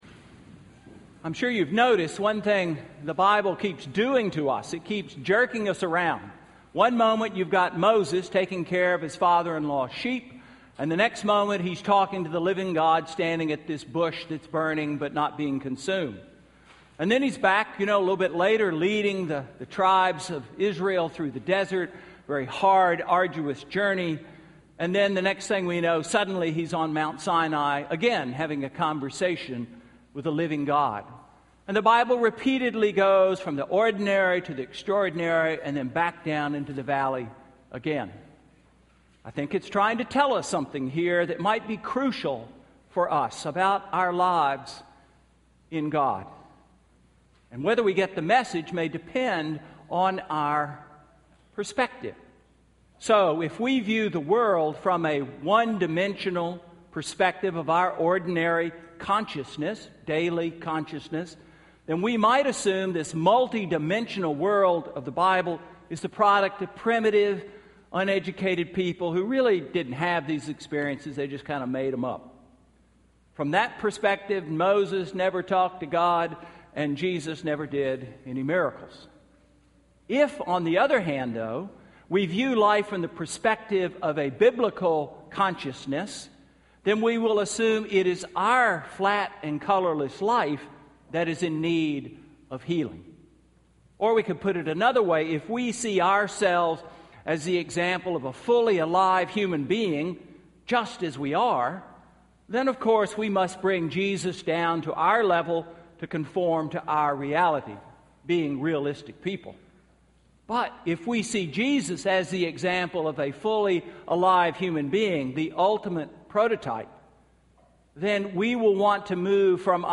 Sermon–March 2, 2014